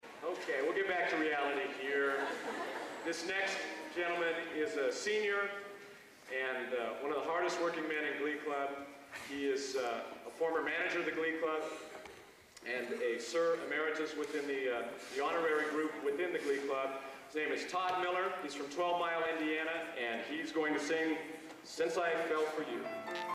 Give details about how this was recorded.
Location: Purdue Memorial Union, West Lafayette, Indiana